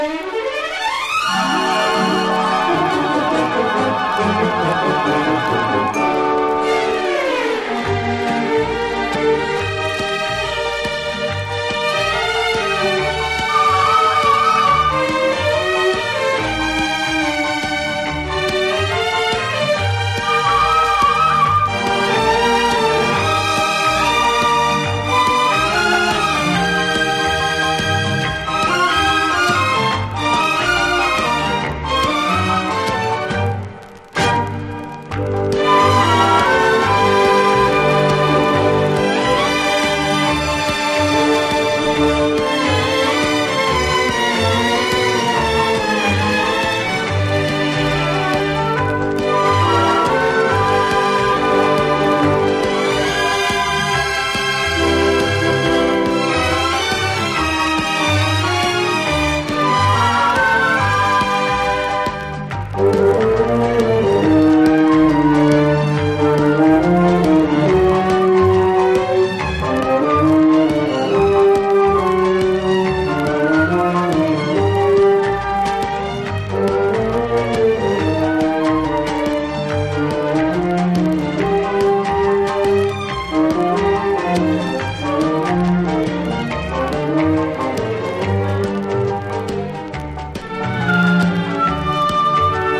とてつもなく優美でロマンティックな魅惑のストリングス・ラウンジ！